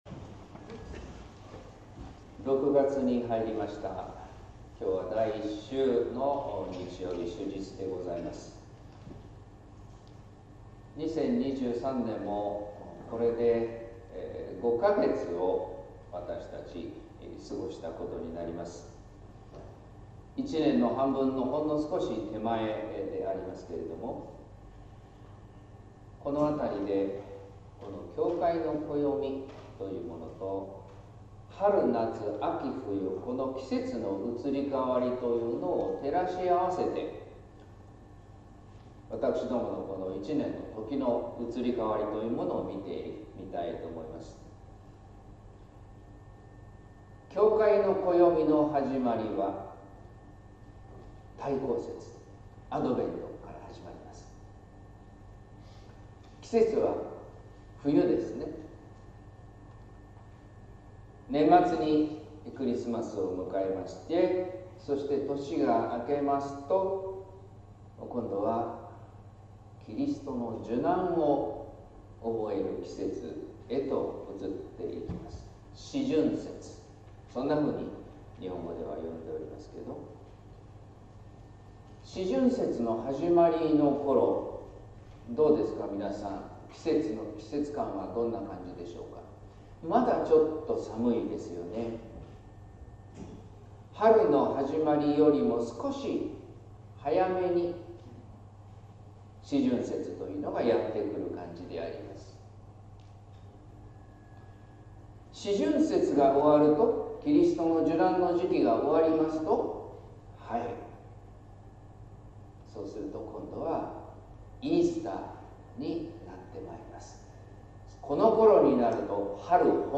説教「父と子と聖霊の神」（音声版） | 日本福音ルーテル市ヶ谷教会